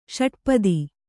♪ ṣaṭpadi